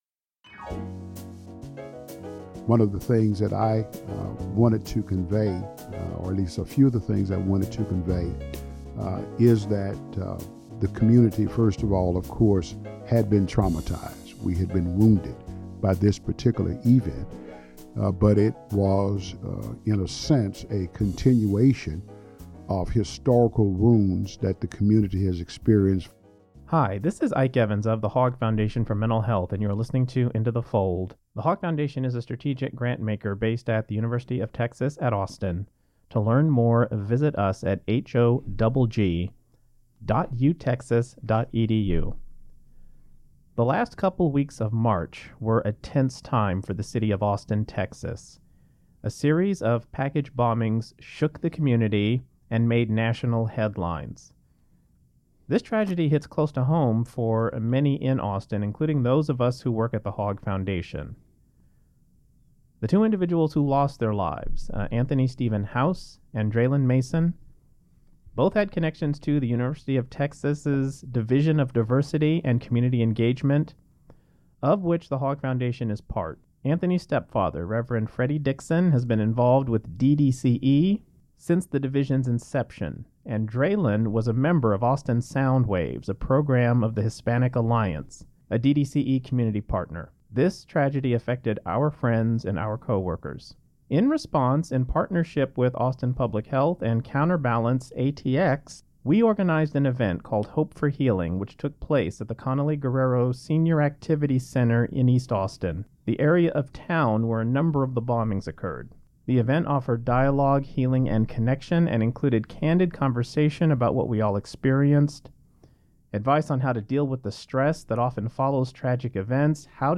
In this conversation, he share his thoughts on the role faith has to play in community responses to, and ultimate recovery from, trauma.